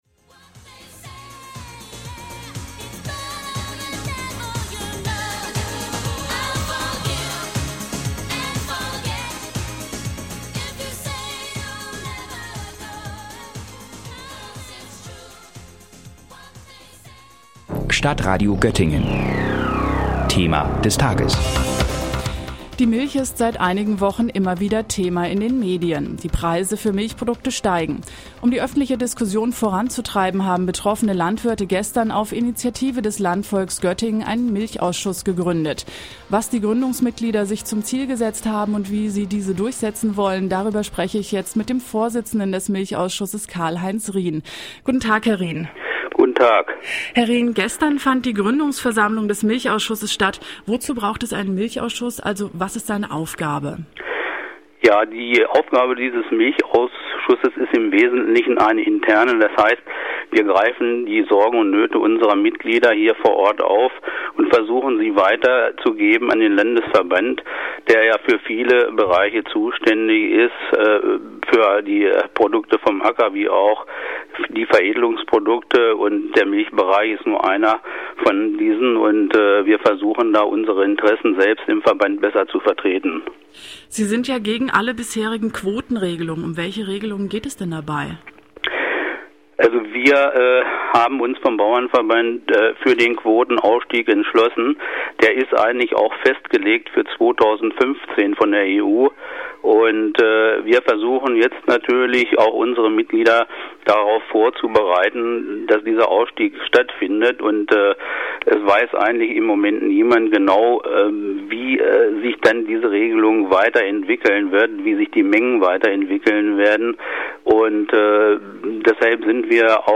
Beitrag dazu vom Stadtradio Göttingen